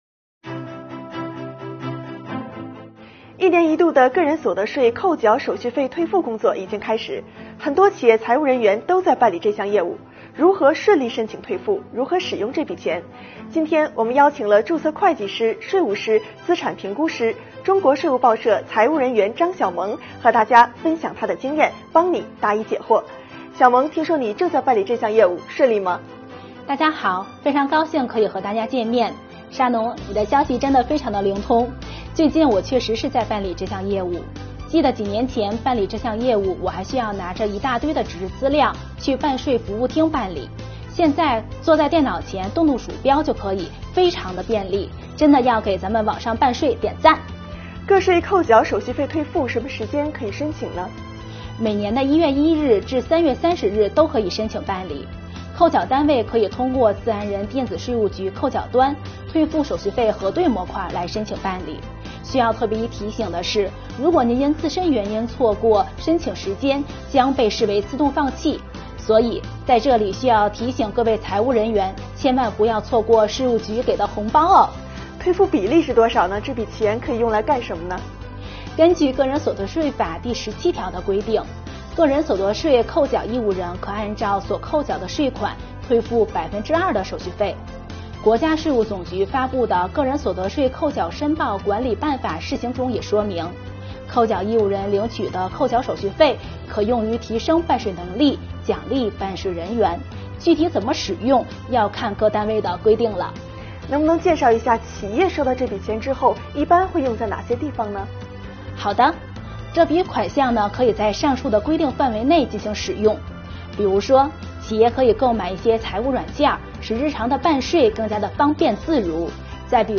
标题: 税收热点访谈丨这笔钱要抓紧申请退了！